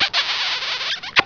Beso.wav